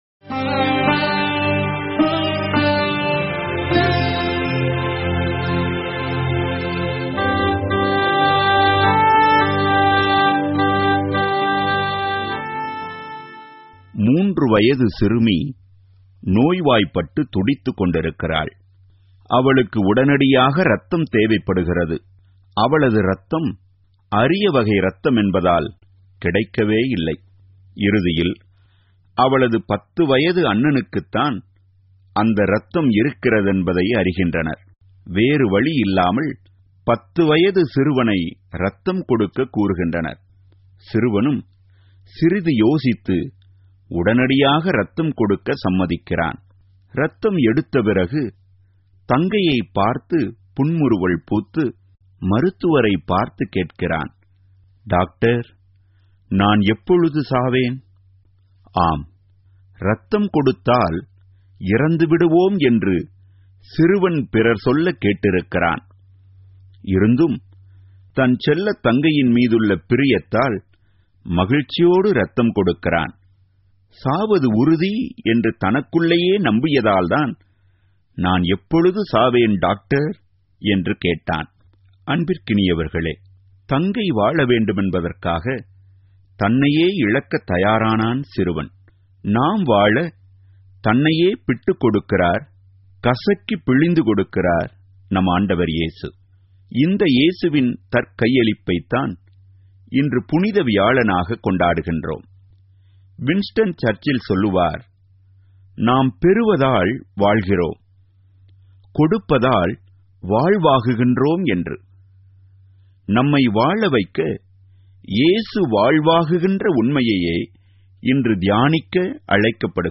Homilies Lenten